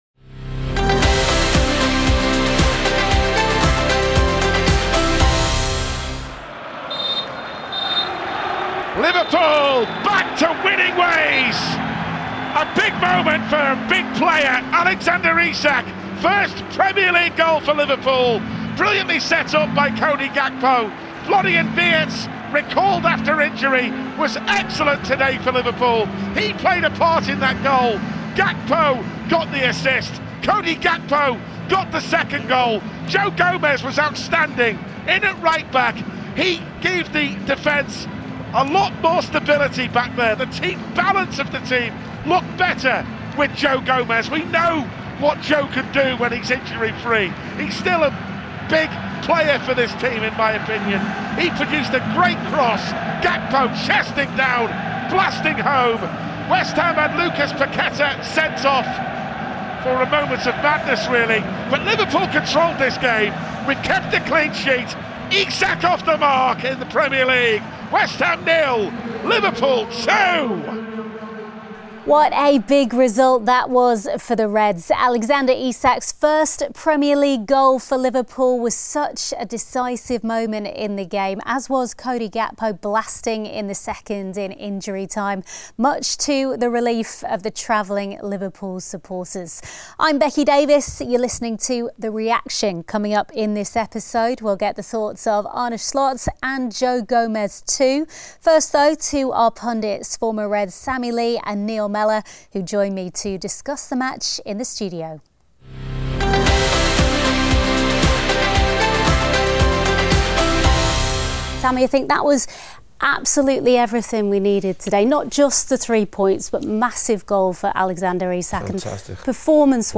In this episode of The Reaction, Arne Slot and Joe Gomez give their assessment of the encounter at the London Stadium; we also bring you post-match analysis from former Reds Sammy Lee, Neil Mellor and Ray Houghton.